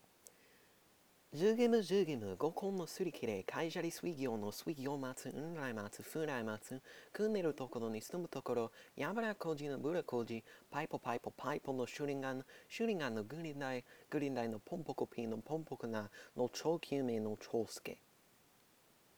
'주게무'라는 이름 전체를 모두 발음한 음원